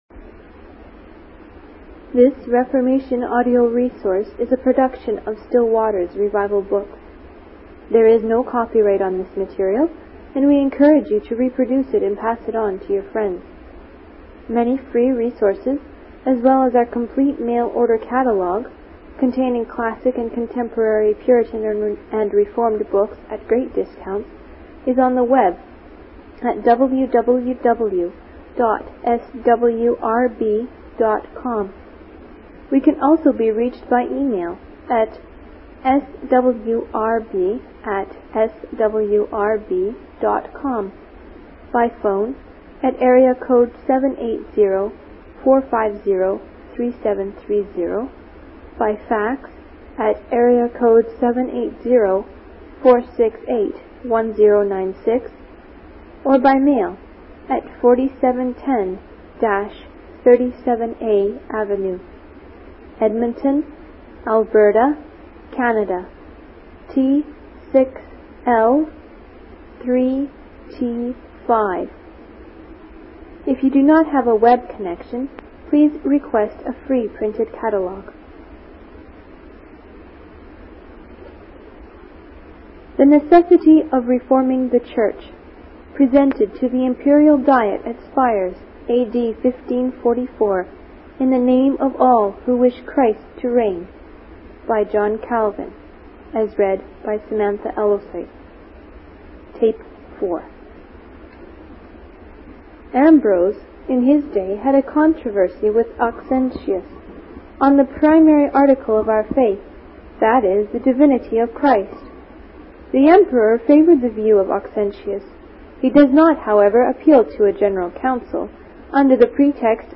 Full Transcript This Reformation audio resource is a production of Stillwater's revival book.